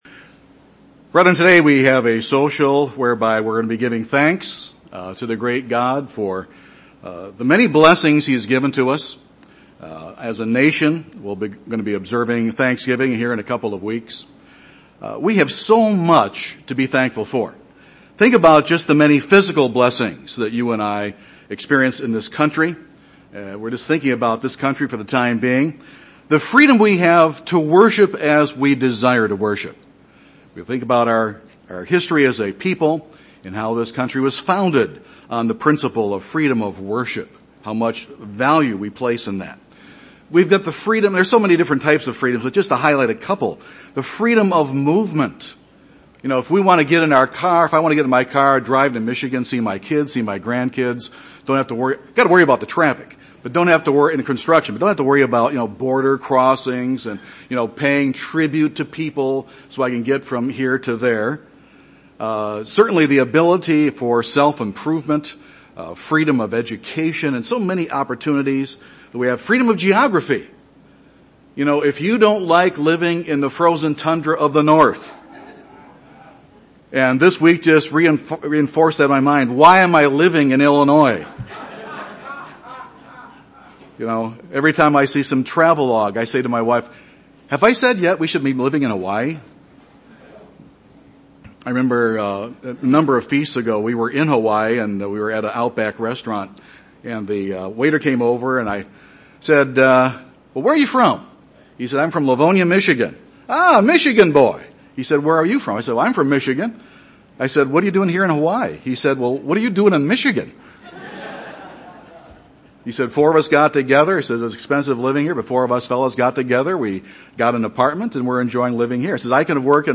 This sermon examines the Old Testament Burnt Offering sacrifice in light of our worship of God today. What is God's perspective and thinking on how He would want us to worship Him?